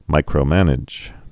(mīkrō-mănĭj)